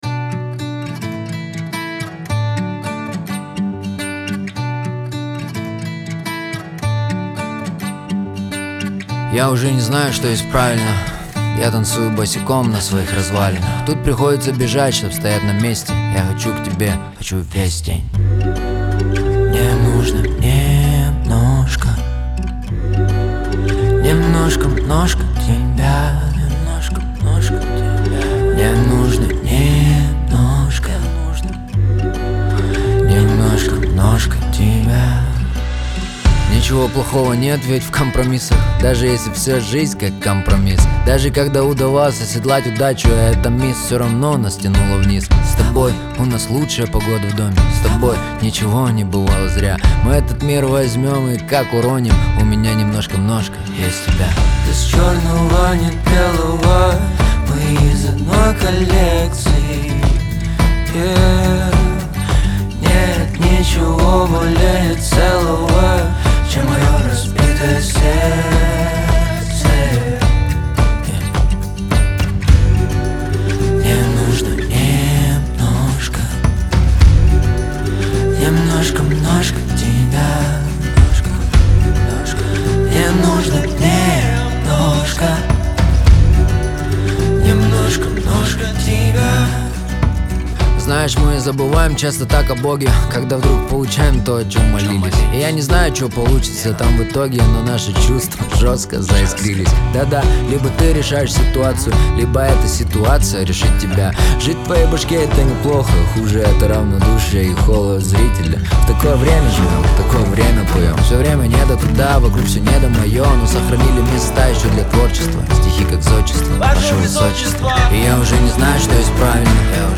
ХАУС-РЭП
эстрада